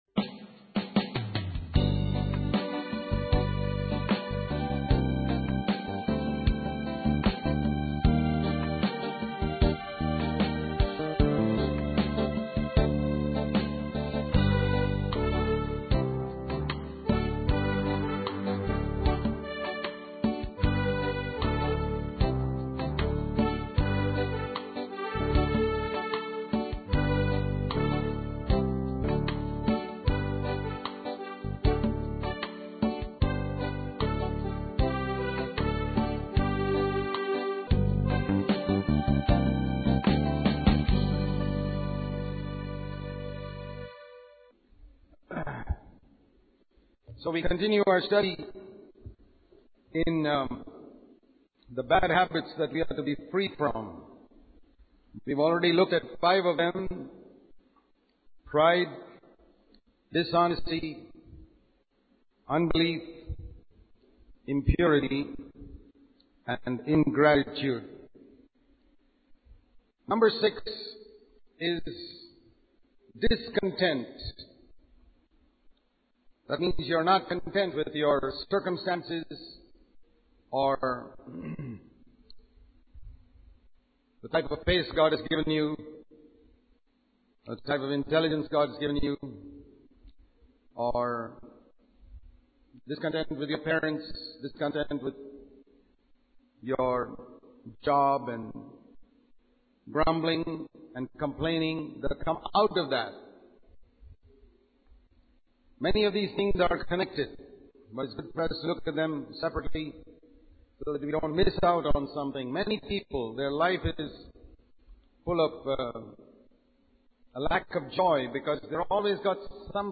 Sermons in this Series